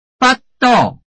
臺灣客語拼音學習網-客語聽讀拼-饒平腔-入聲韻
拼音查詢：【饒平腔】bad ~請點選不同聲調拼音聽聽看!(例字漢字部分屬參考性質)